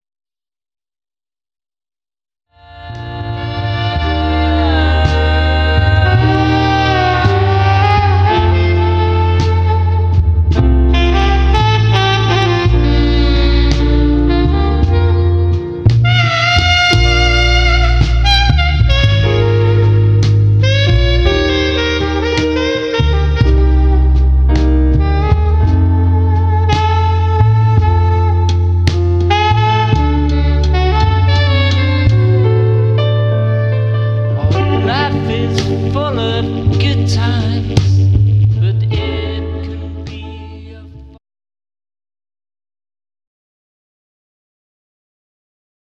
vocals, guitar, percussion
piano, bass, saxophone